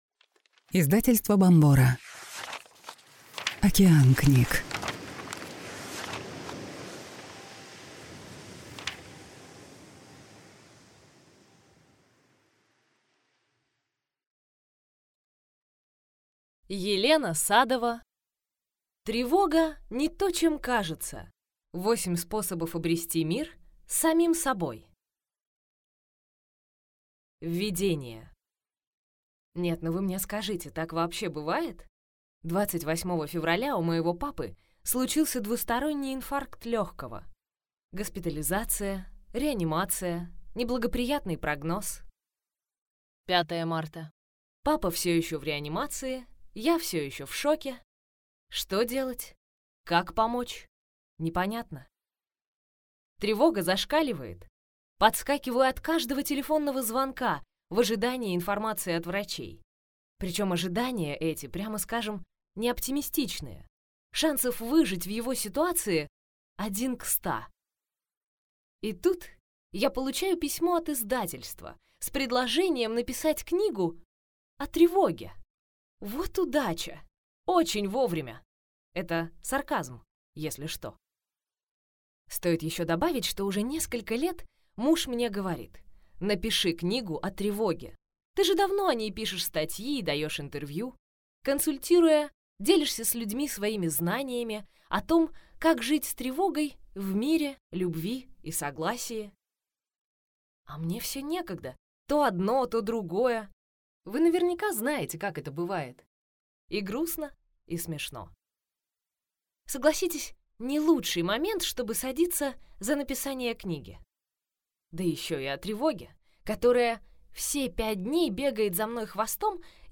Аудиокнига Тревога не то, чем кажется. 8 способов обрести мир с самим собой | Библиотека аудиокниг